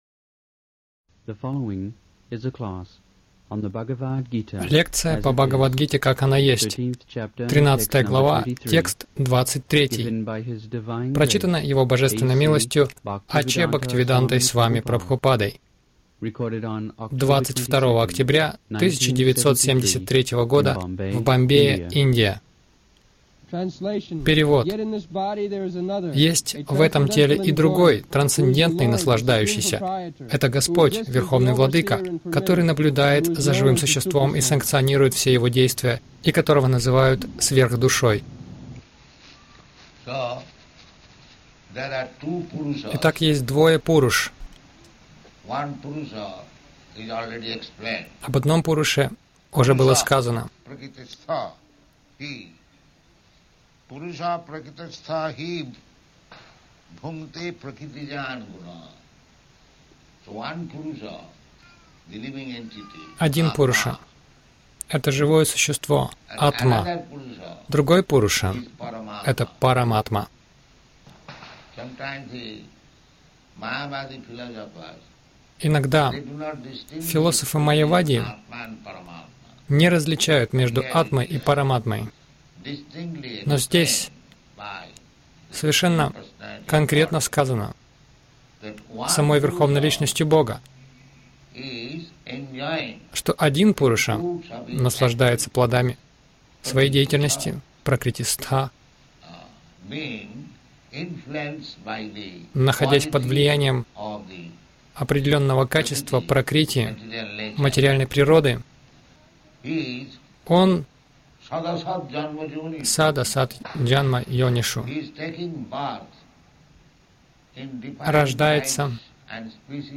Скачать лекцию Назад Далее Слушать ещё: БГ 02.11 — Всегда есть Высшая власть БГ 13.18 — Вселенское сияние Кришны БГ 02.26-27 — Не горюйте над кучкой химикатов БГ 13.08-12 — Необходимо приблизиться к ачарье